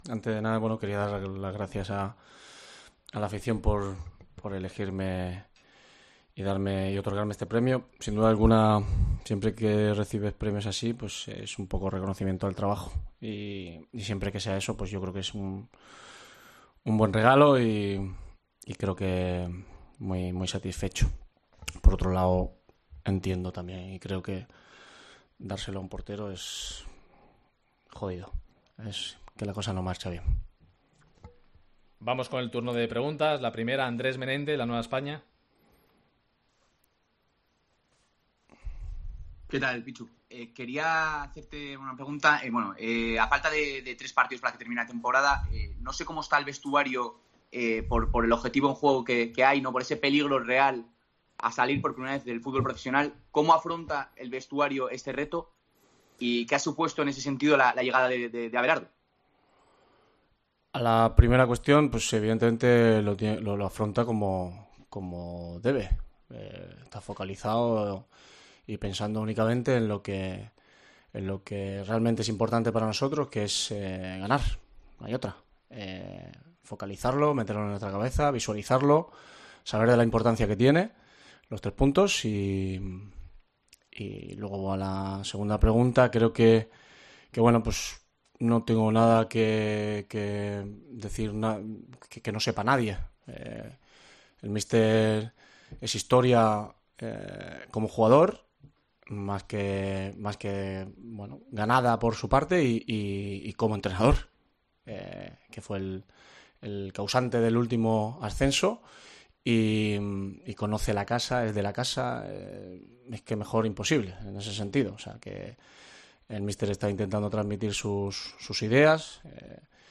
Rueda de prensa Iván Cuéllar (MVP abril)